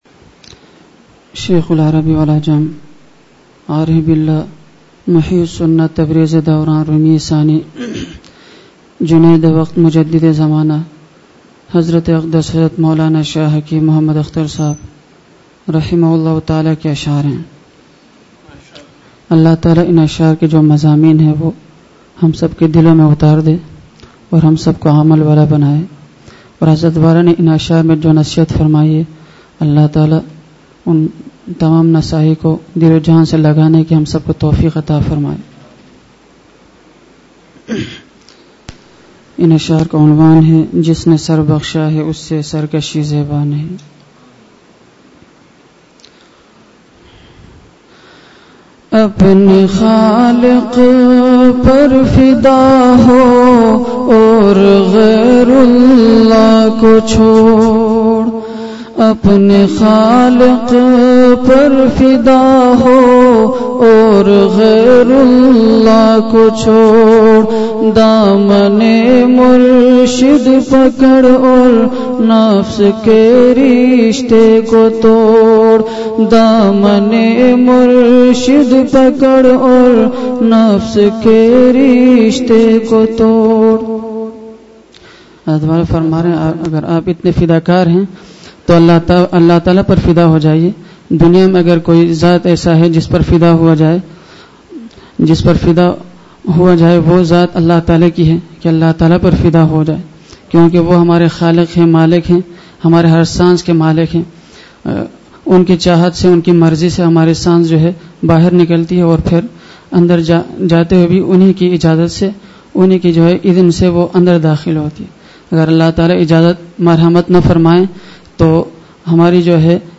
درد بھرا بیان براہ راست بذریعہ انٹرنیٹ سنوایا گیا
اصلاحی مجلس